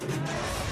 speeder_boost.wav